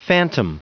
Prononciation du mot phantom en anglais (fichier audio)
Prononciation du mot : phantom